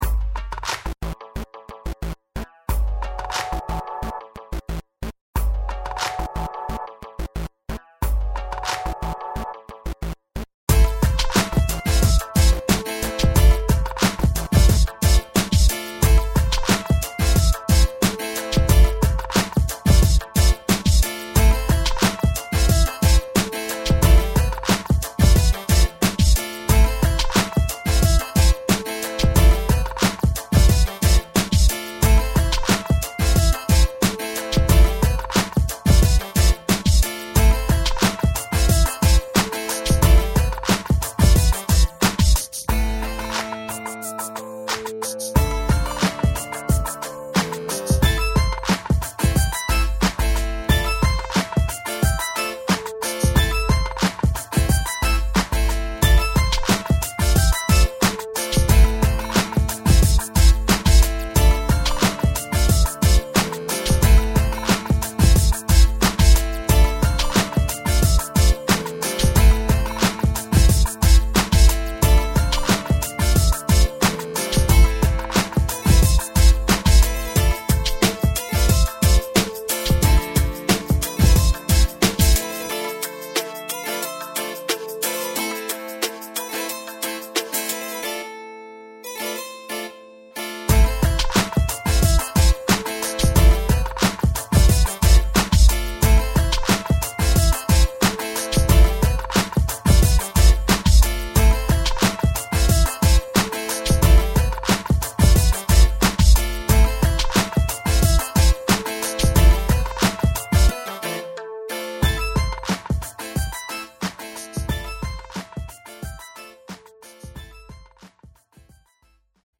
• Жанр: Хип-хоп